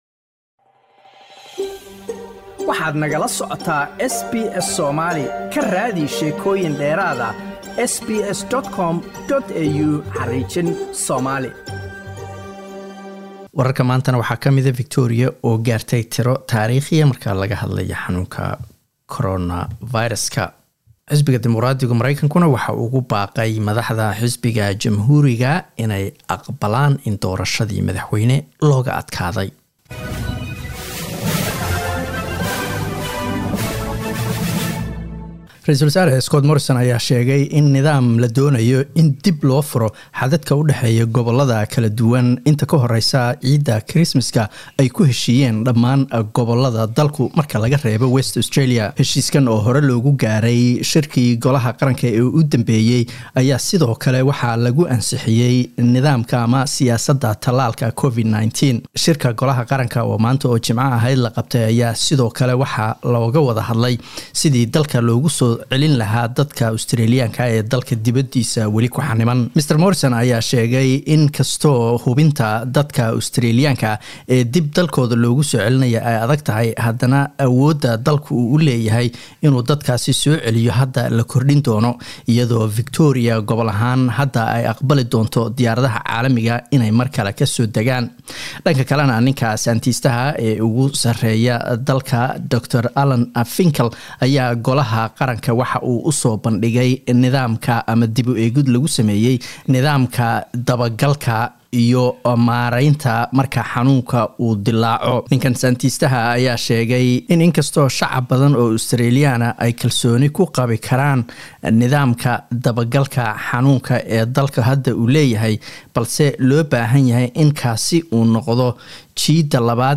Wararka SBS Somali Jimco 13 Nofember